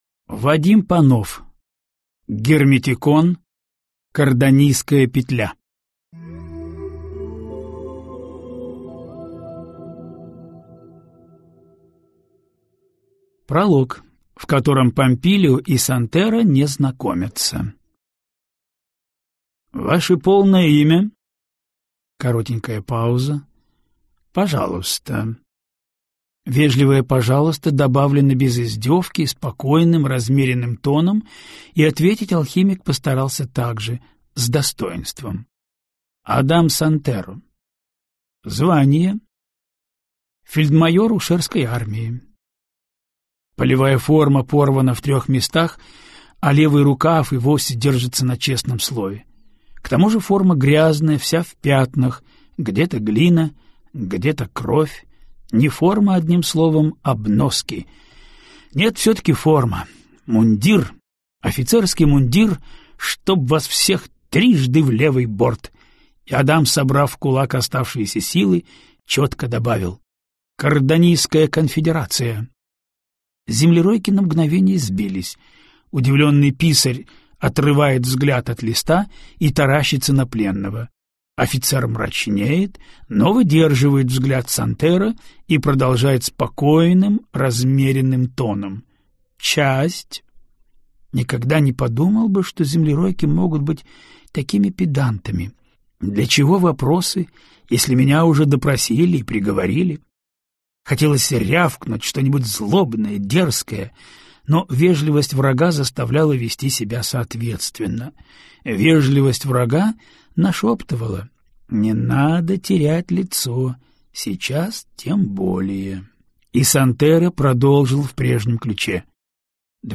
Аудиокнига Кардонийская петля | Библиотека аудиокниг
Прослушать и бесплатно скачать фрагмент аудиокниги